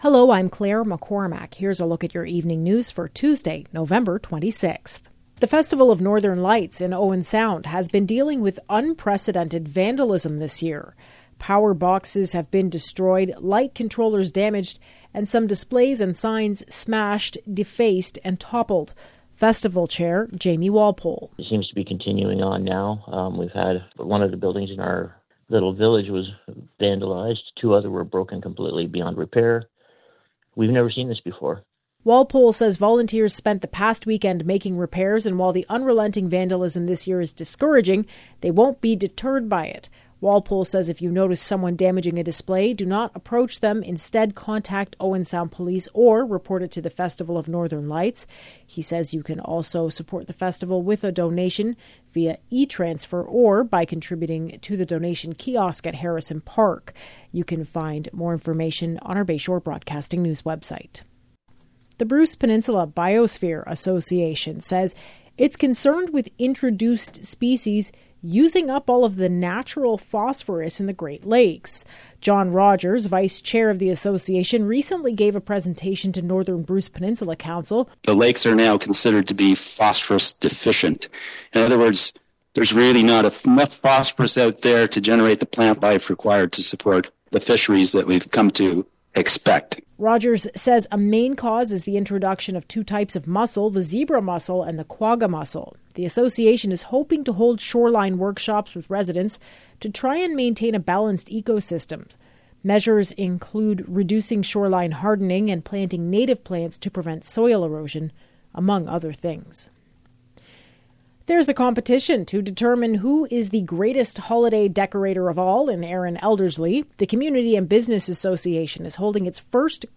Evening News – Tuesday, November 26